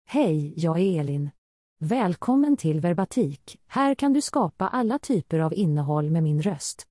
Elin — Female Swedish AI voice
Elin is a female AI voice for Swedish.
Voice sample
Female
Elin delivers clear pronunciation with authentic Swedish intonation, making your content sound professionally produced.